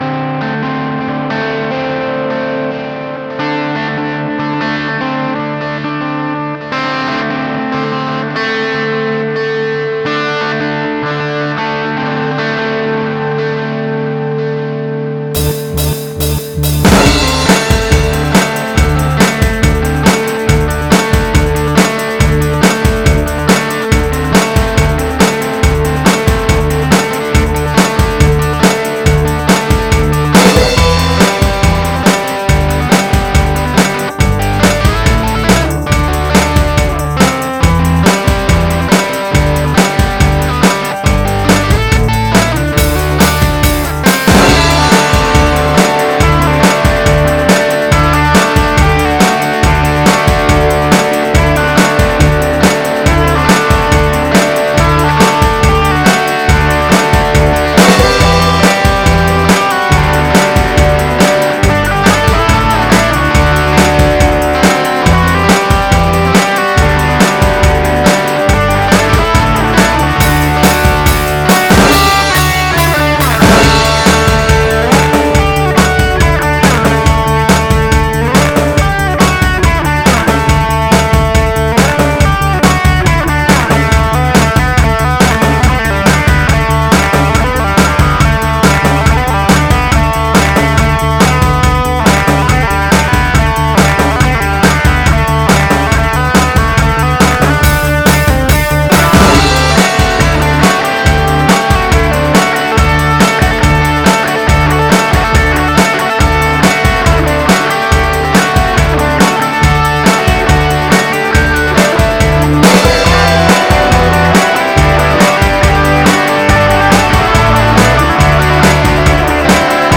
Epiphone Les Paul CustomI know that after a long weekend of partying and relaxing, what you want is some amateurish songwriting and geetar playing to get you psyched up for the work week to come.
No, I cannot do anything about the general quality of the mix, as I am incompetent.